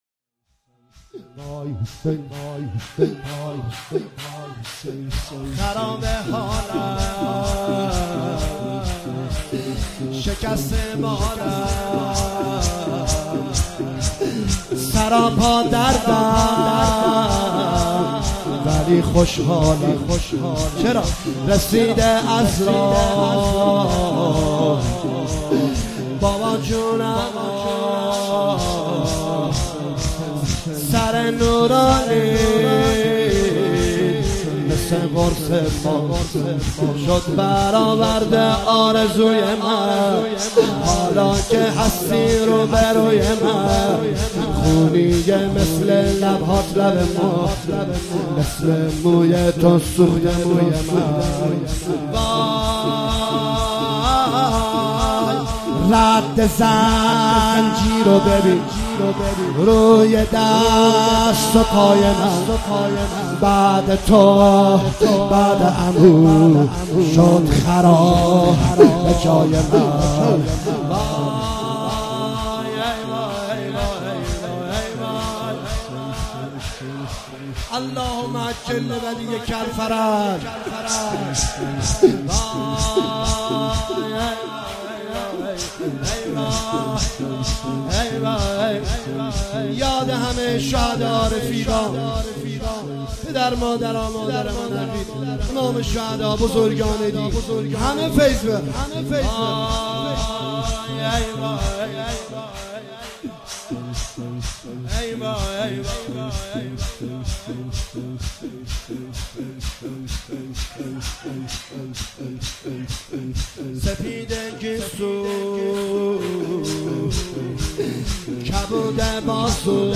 مداحی جدید
شور